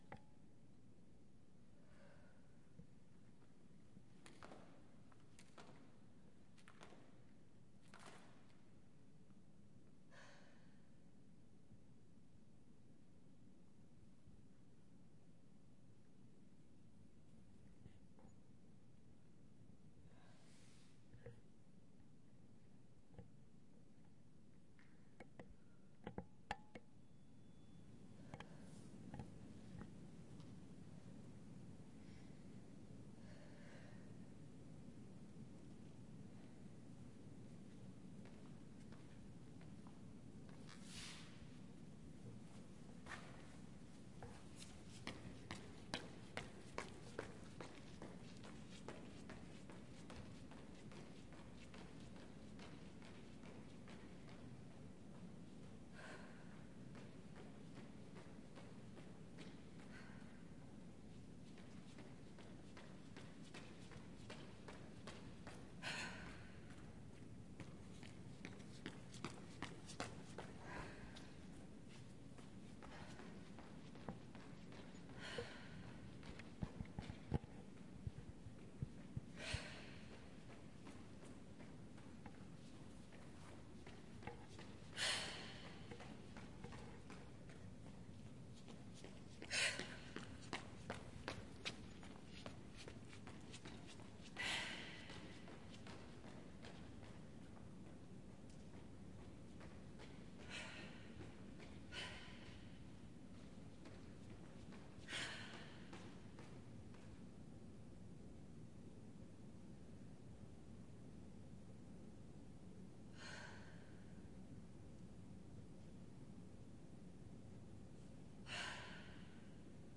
描述：在圣克鲁斯德特内里费的一个博物馆TEA录制。
在这里，我们可以听到她在一个大殿里奔跑，呼吸和喊叫。
Tag: 场记录 运行 跑步 一口气